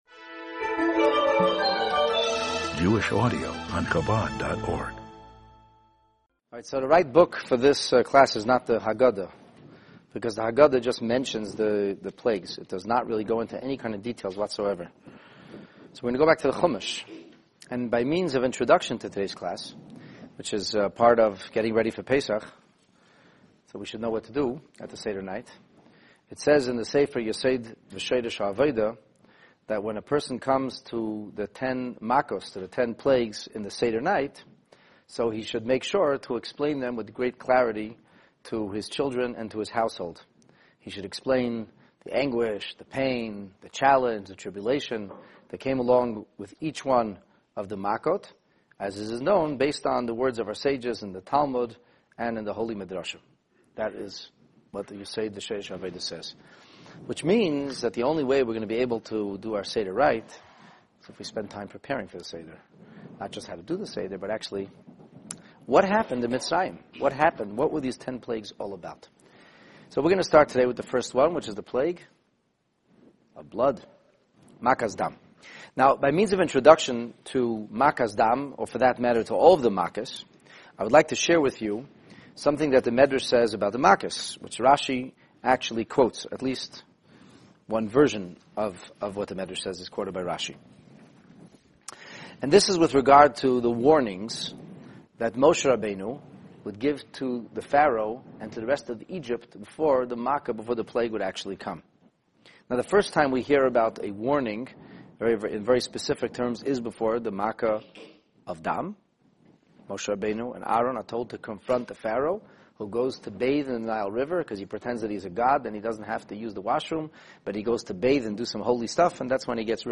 The Ten Plagues: 1 Blood (Dam) This class pierces the veil shrouding the mystery around the first of the proverbial 10 Plagues. Discover the full story of the Nile River miraculously running red.